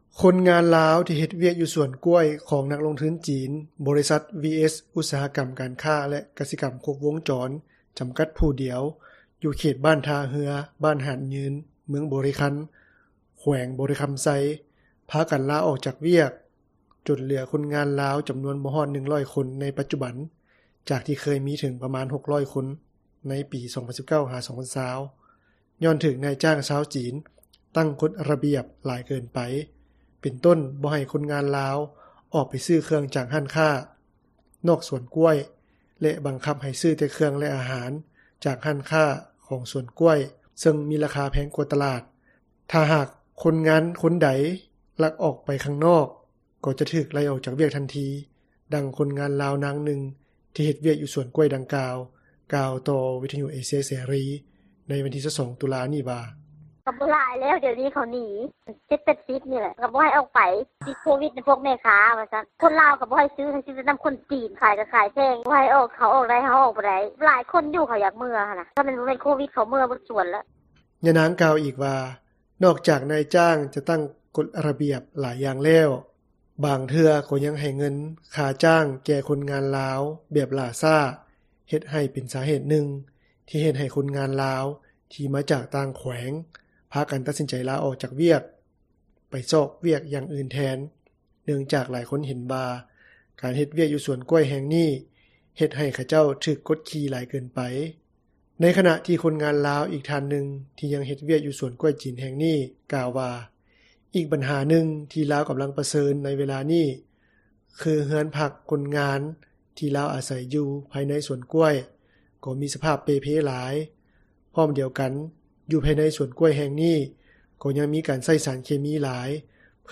ດັ່ງຄົນງານລາວ ນາງນຶ່ງ ທີ່ເຮັດວຽກຢູ່ສວນກ້ວຍດັ່ງກ່າວ ກ່າວຕໍ່ວິທຍຸເອເຊັຽເສຣີ ໃນວັນທີ 22 ຕຸລາ ນີ້ວ່າ: